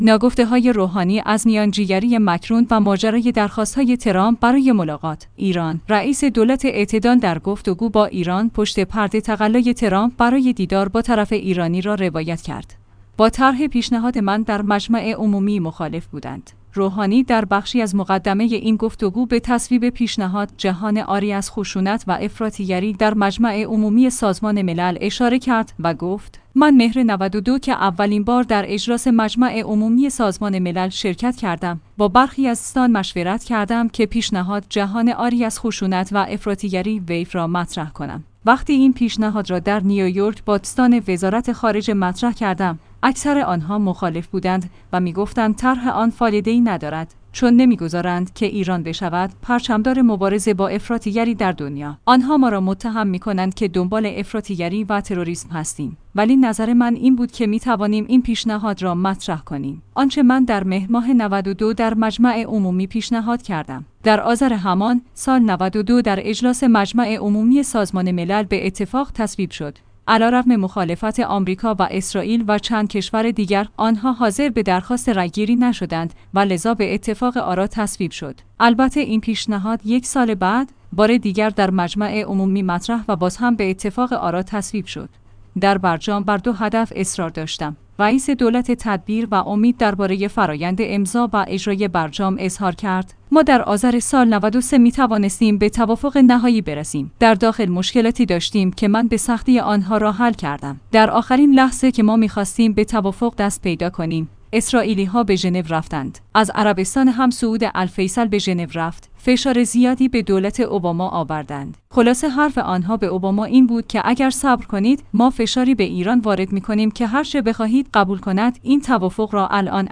ایران/ رئیس دولت اعتدال در گفت‌وگو با «ایران» پشت‌پرده تقلای ترامپ برای دیدار با طرف ایرانی را روایت کرد.